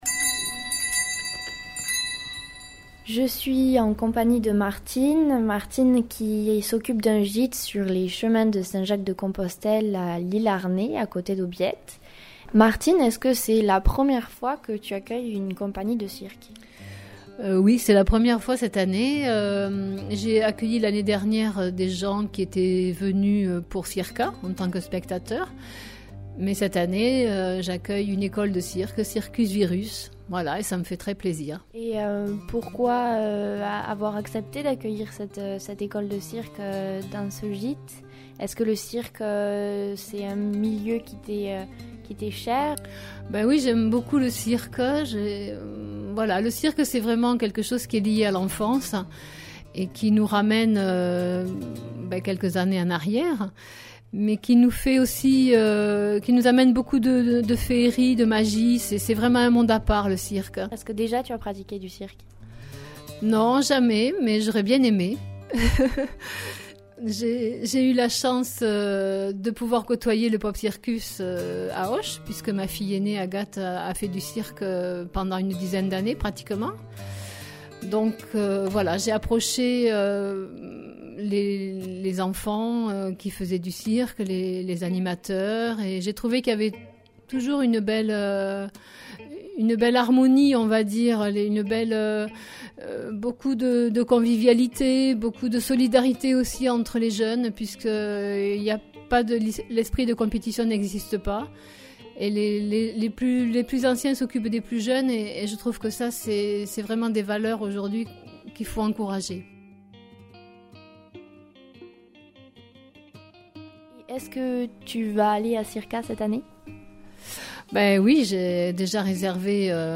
reportage_circus_virus_circa.mp3